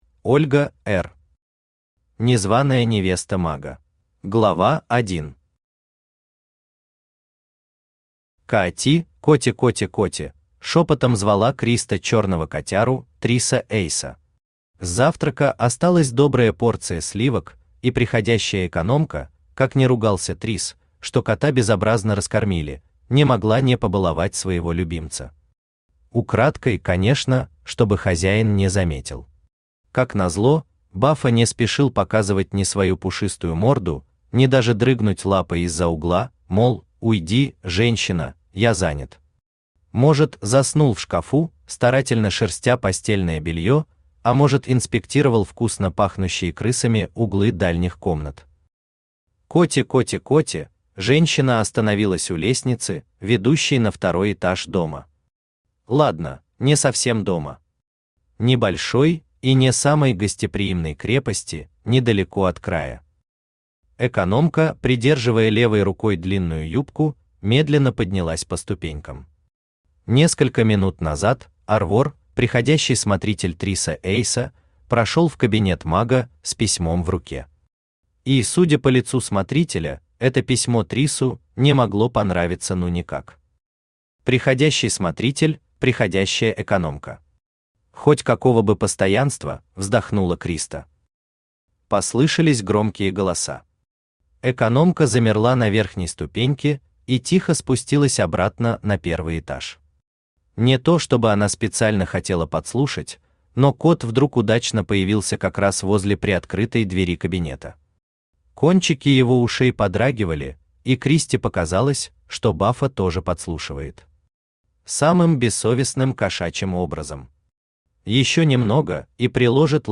Aудиокнига Незваная невеста мага Автор Ольга Эр Читает аудиокнигу Авточтец ЛитРес.